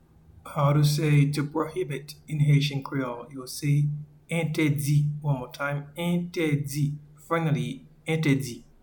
Pronunciation:
to-Prohibit-in-Haitian-Creole-Entedi.mp3